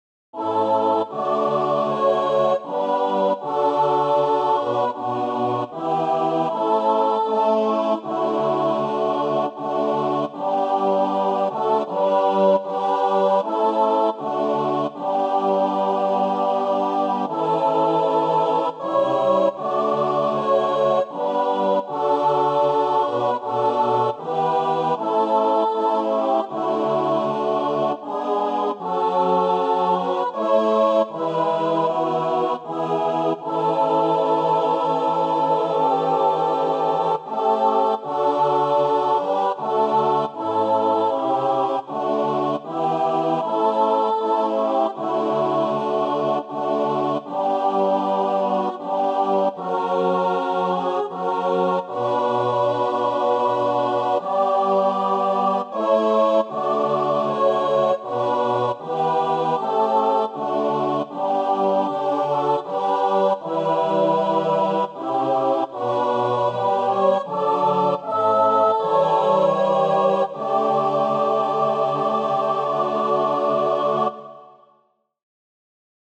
SATB, Youth Choir Mixed Or Unison
Voicing/Instrumentation: SATB , Youth Choir Mixed Or Unison